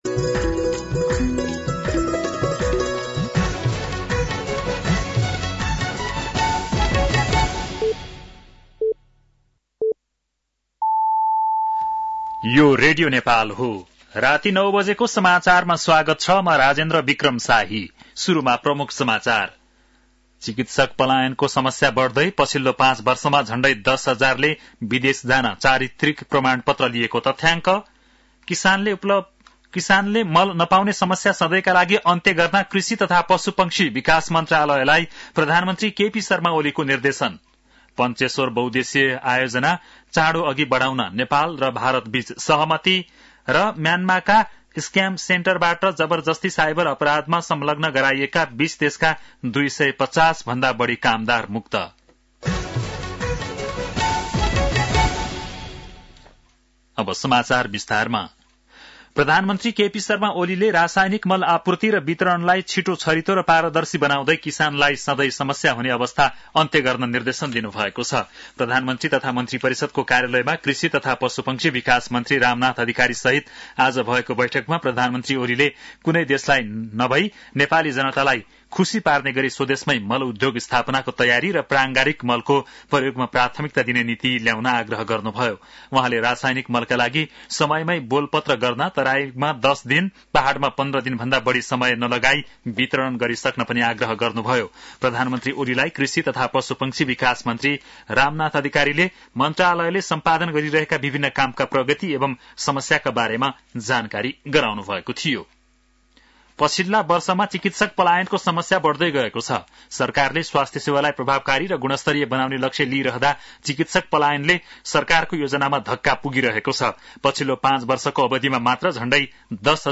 An online outlet of Nepal's national radio broadcaster
बेलुकी ९ बजेको नेपाली समाचार : २ फागुन , २०८१
9-PM-Nepali-NEWS-11-01.mp3